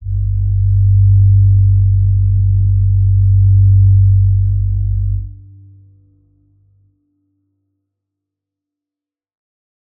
G_Crystal-G2-pp.wav